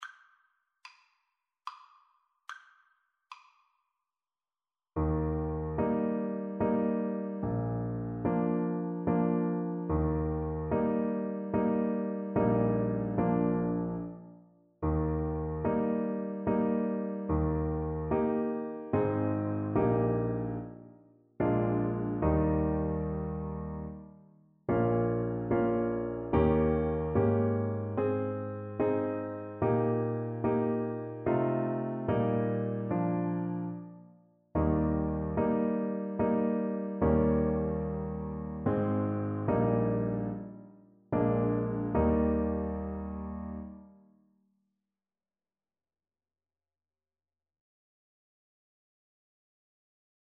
3/4 (View more 3/4 Music)
Flowing
Bb4-Eb6
Scottish